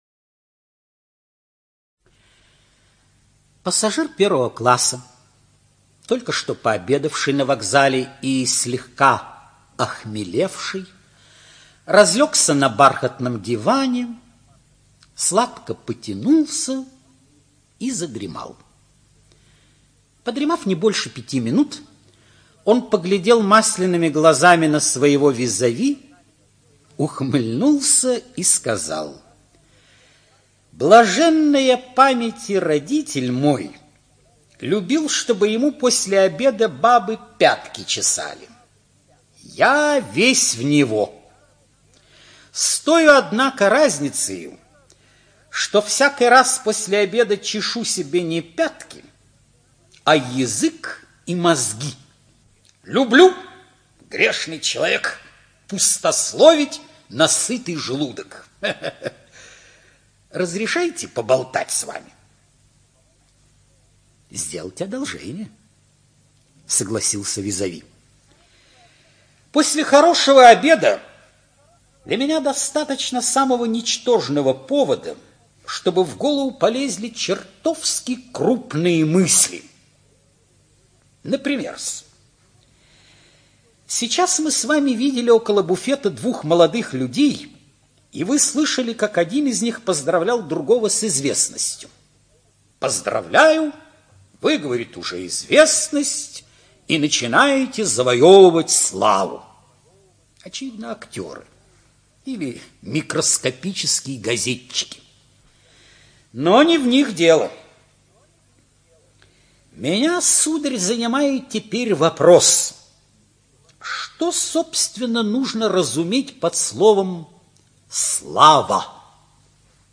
ЧитаетКаминка Э.